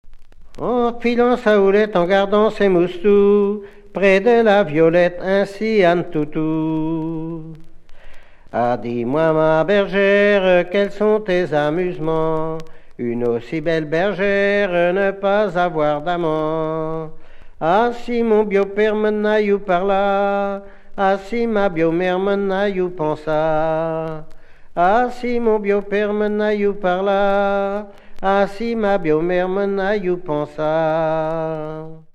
Genre dialogue
Pièce musicale inédite